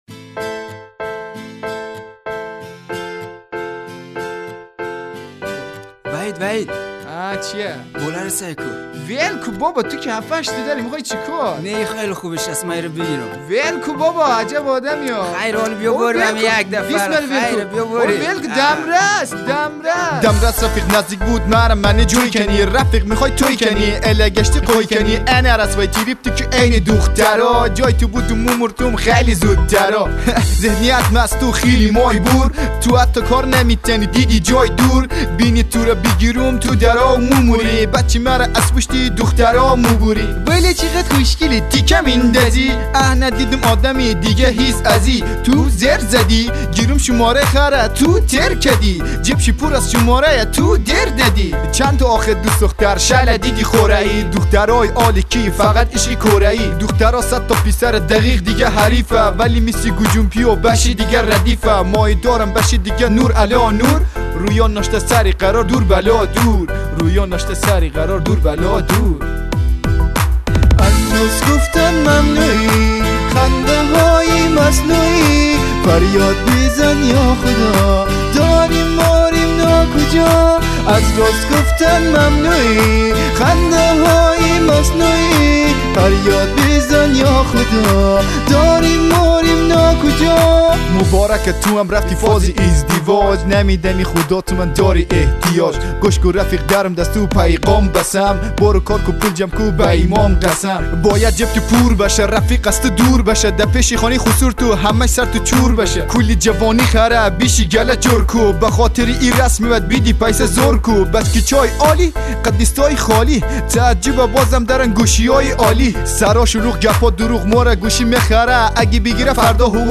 با لهجه هزارگی(فارسی دری)